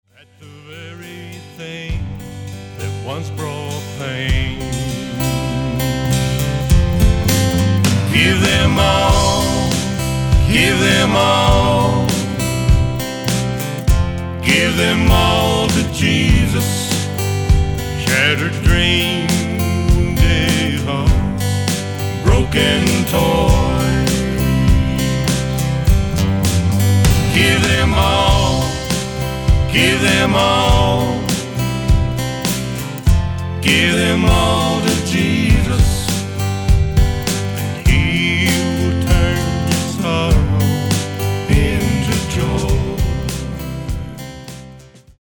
Autoharp, Lead & Harmony Vocals
Drums, Lead & Harmony Vocals
Guitar, Lead & Harmony Vocals
Keyboards, Bass Vocals